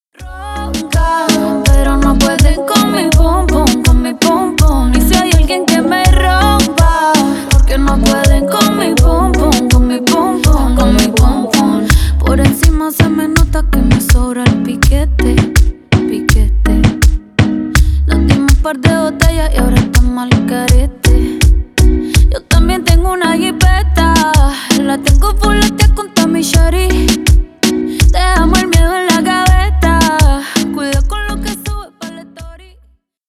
Танцевальные
латинские